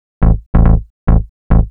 Techno / Bass / SNTHBASS112_TEKNO_140_A_SC2.wav
1 channel